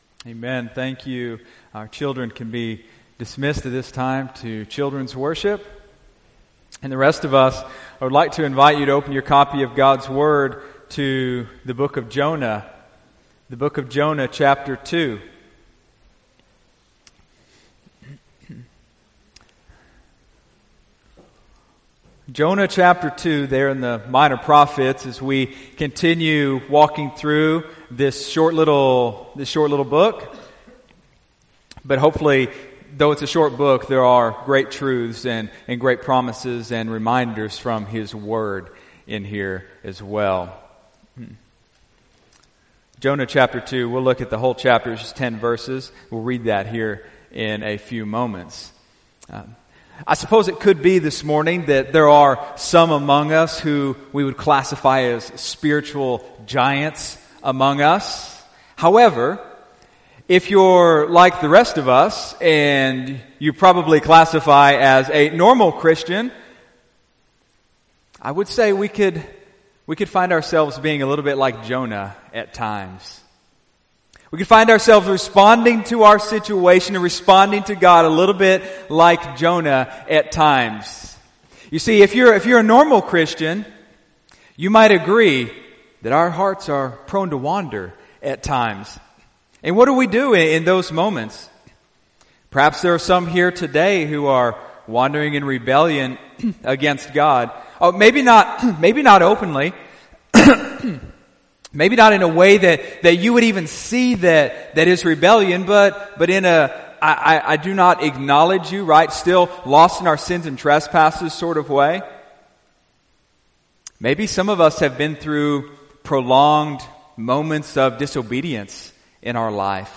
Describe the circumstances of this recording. Sunday, April 22, 2018 (Sunday Morning Service)